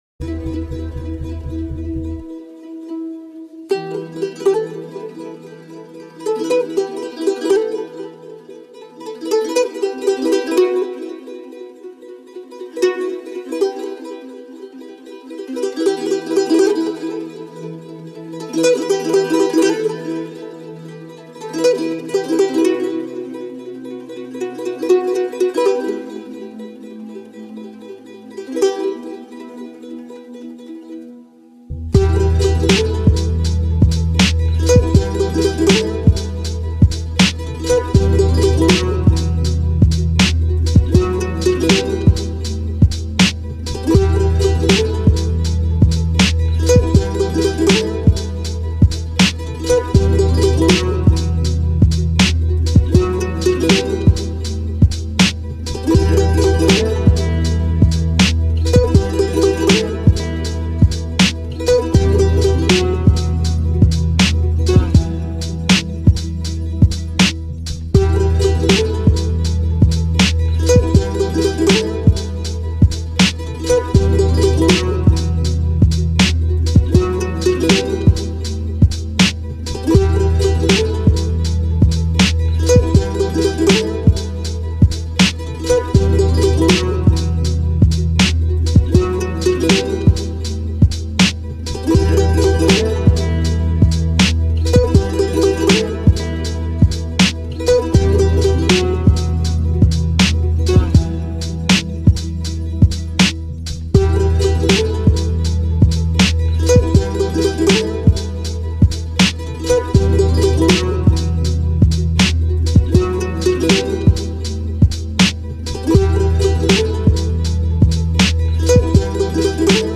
موزیک بی کلام معروف